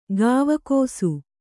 ♪ gāvakōsu